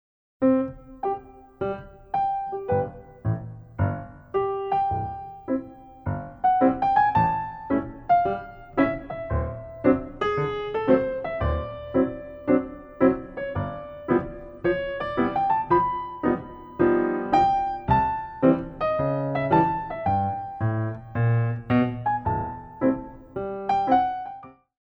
By Pianist & Ballet Accompanist
Tendu
SAB Style